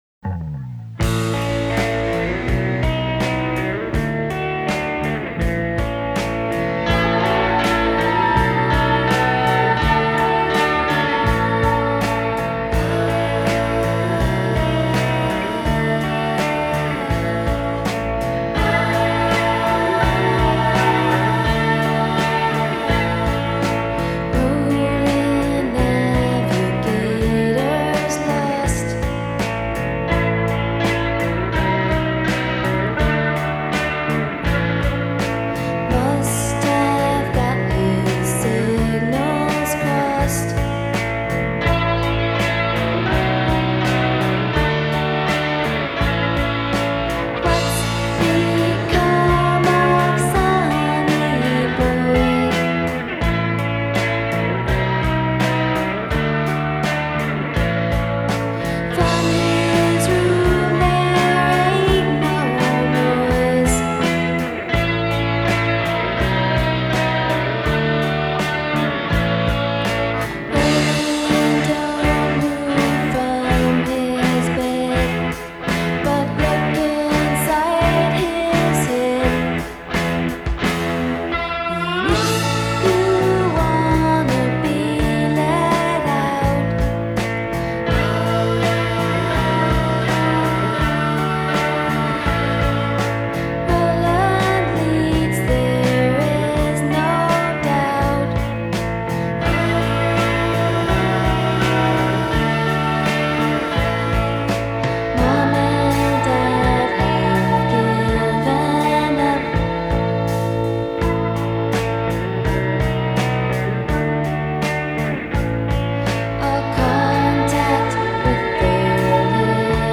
New York Indie in the 90s.